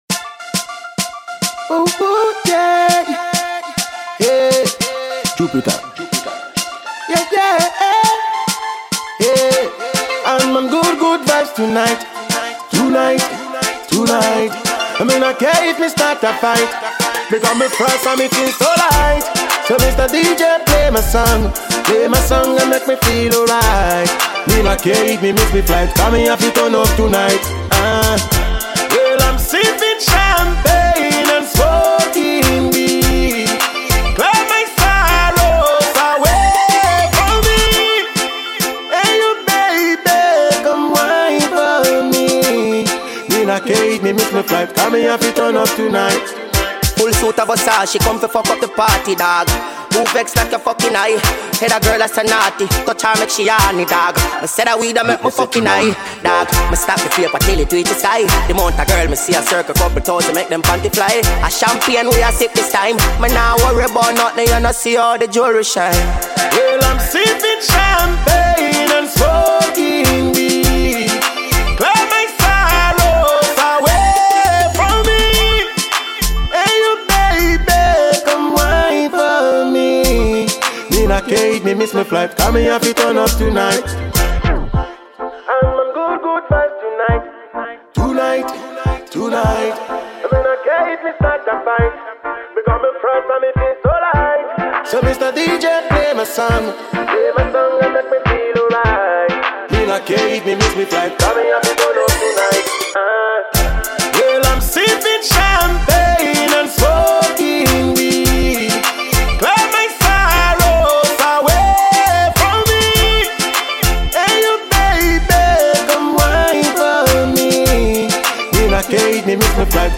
Dancehall/HiphopGhana MusicMusic